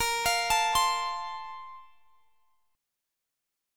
BbM7sus2 Chord
Listen to BbM7sus2 strummed